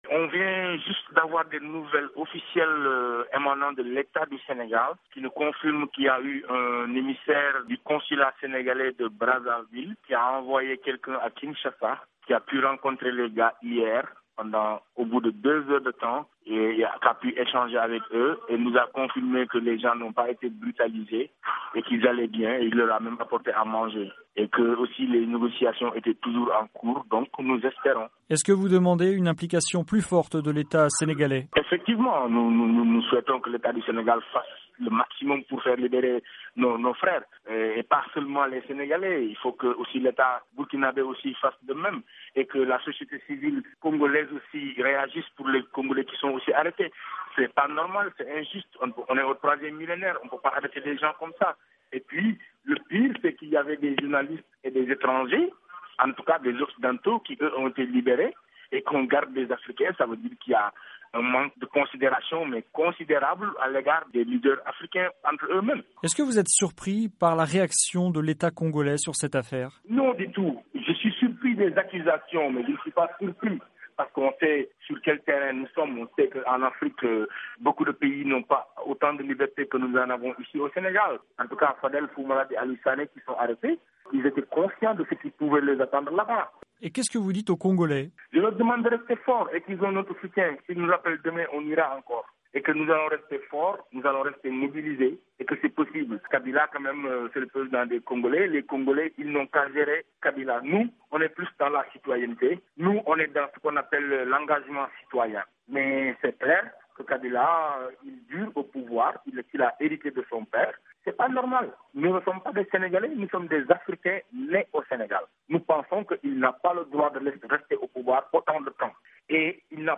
Le rappeur Thiat, membre de "Y en a marre"